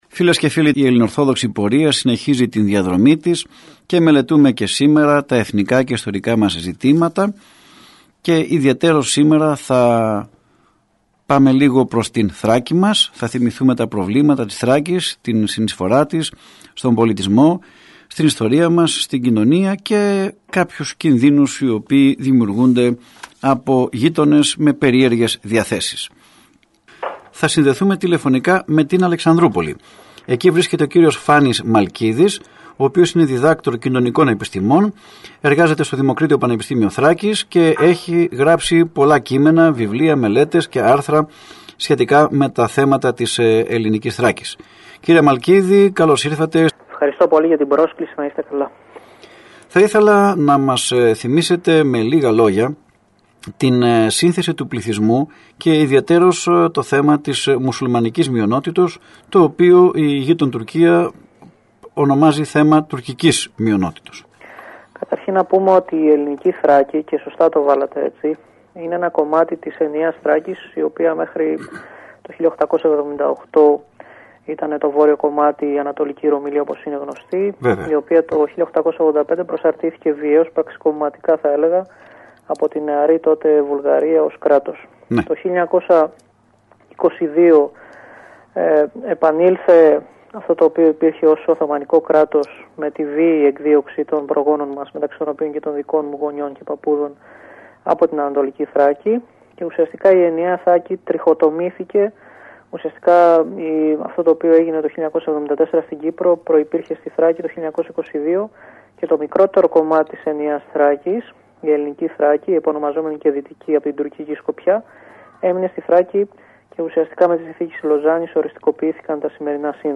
Ακούστε στην συνέχεια, ηχογραφημένα αποσπάσματα της ραδιοφωνικής εκπομπής «Ελληνορθόδοξη πορεία», που μεταδόθηκε από τον ραδιοσταθμό της Πειραϊκής Εκκλησίας.